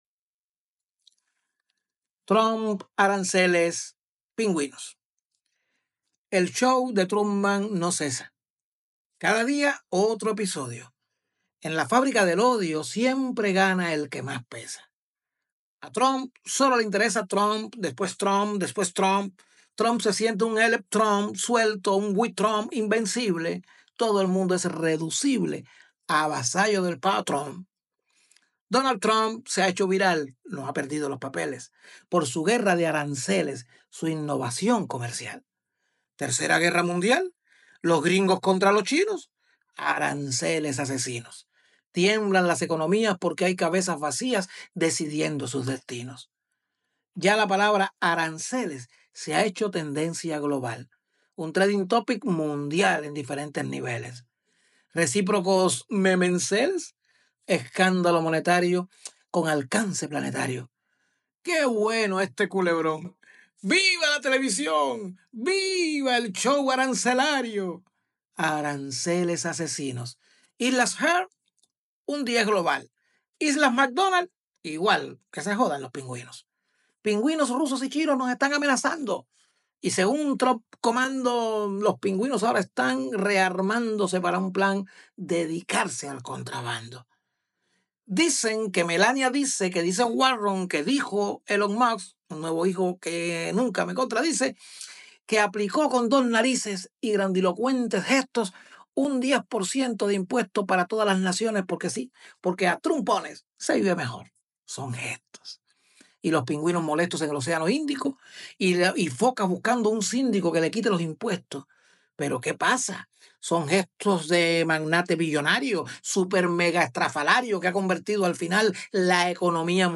Play para escuchar las décimas en voz de su autor Alexis Díaz-Pimienta: